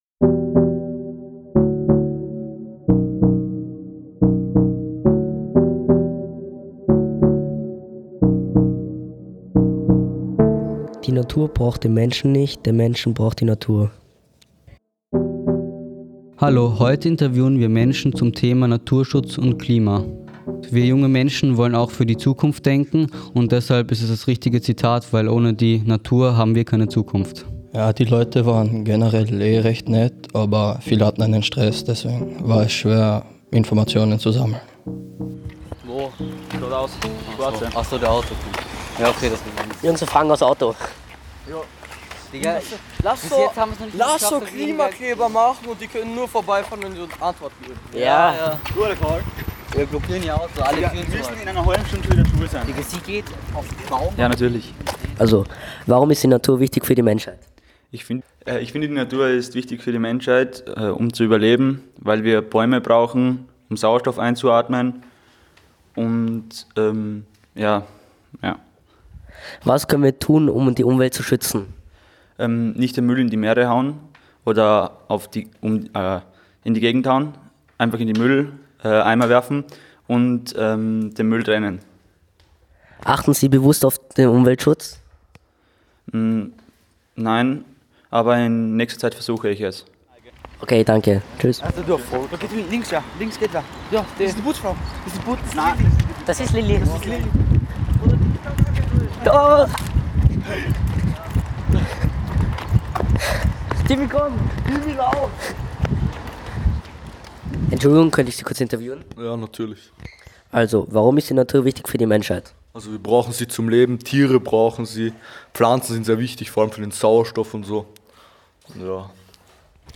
Straßenumfrage: Umweltsituation – Was tun?